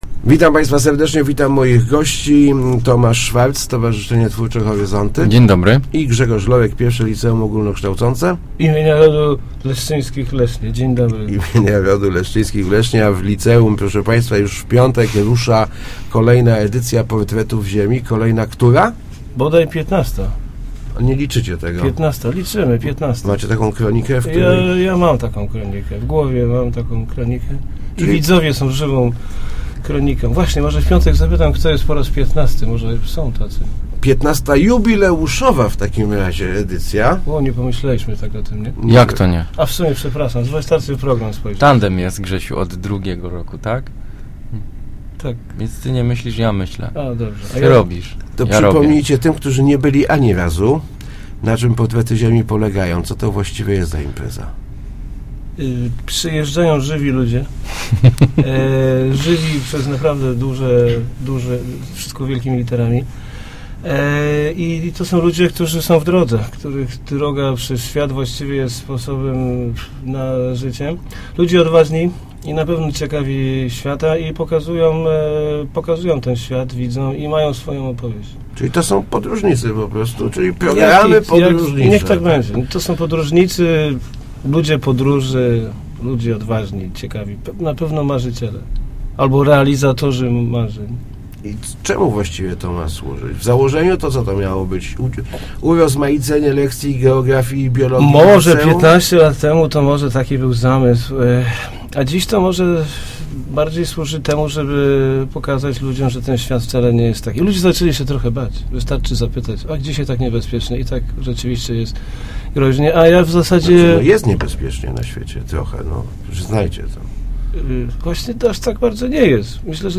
mówili w Rozmowach Elki